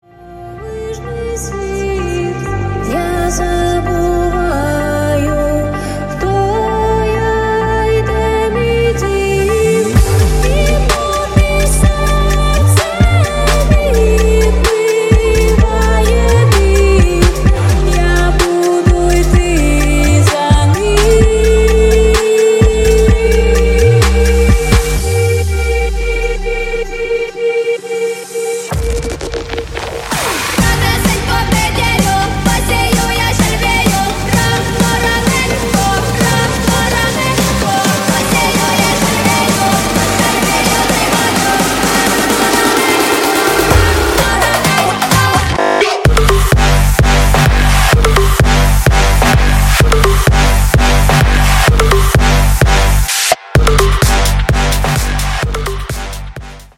громкие
мощные
dance
club
electro house
українська клубна музика